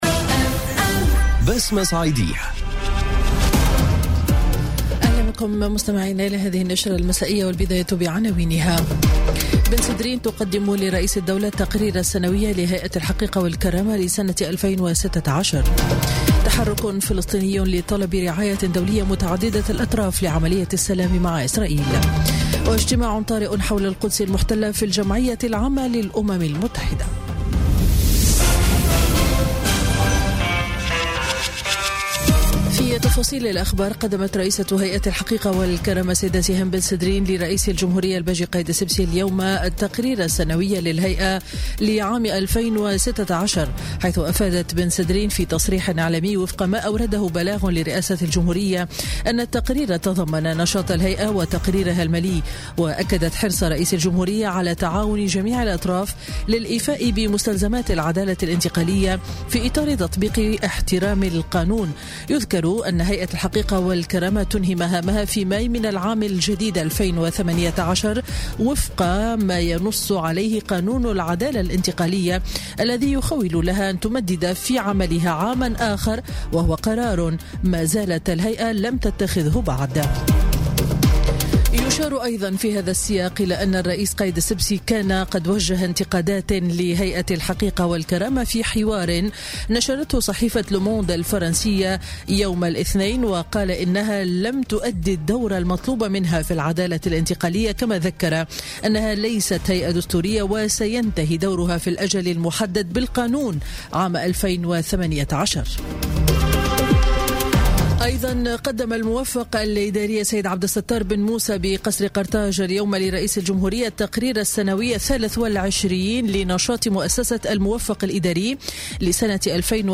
Journal Info 19h00 du Mardi 19 Décembre 2017